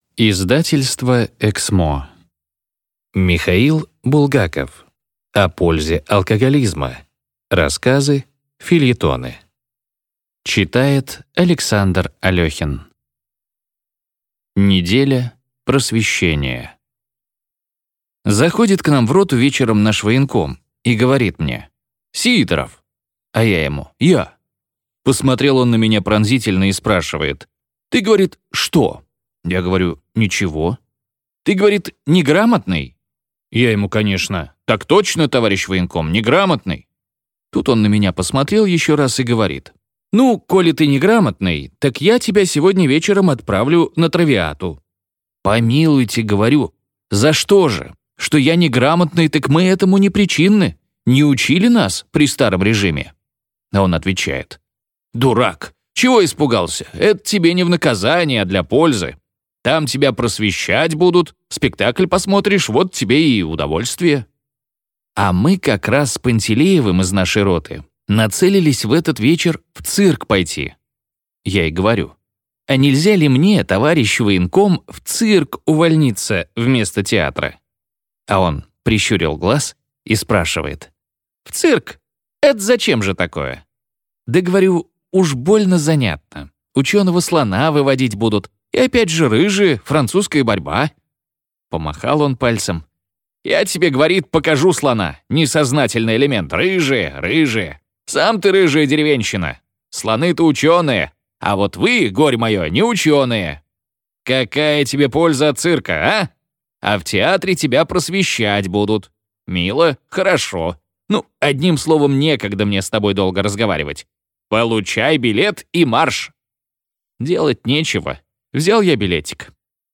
Аудиокнига О пользе алкоголизма. Рассказы. Фельетоны | Библиотека аудиокниг